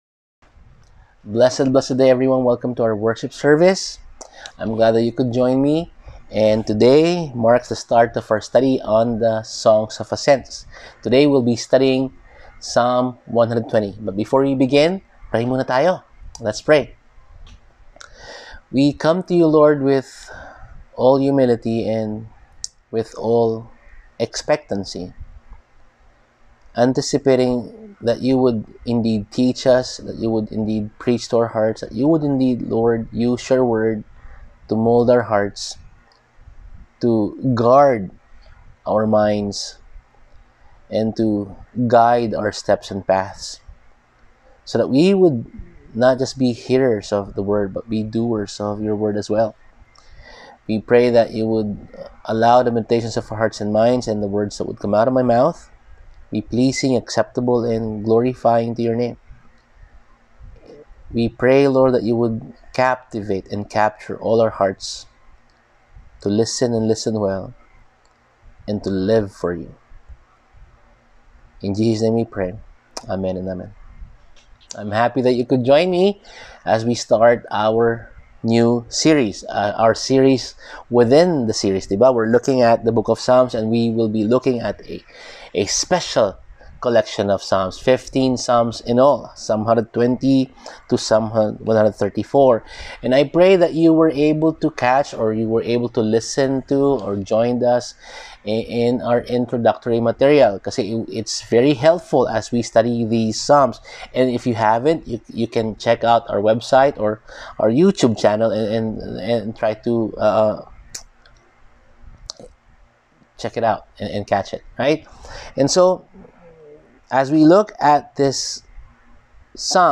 Living Out the Psalms Passage: Psalm 120:1-7 Service: Sunday Sermon Outline